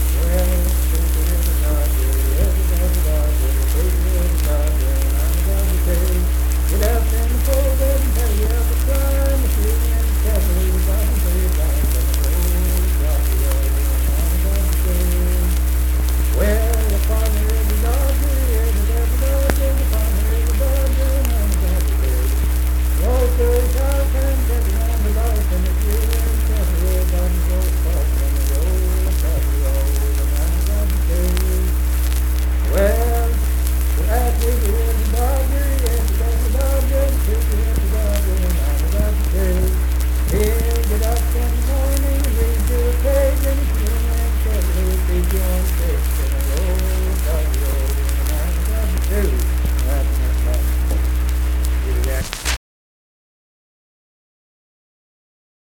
Unaccompanied vocal music
Verse-refrain 3(6w/R). Performed in Kanawha Head, Upshur County, WV.
Voice (sung)